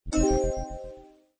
ui_interface_38.wav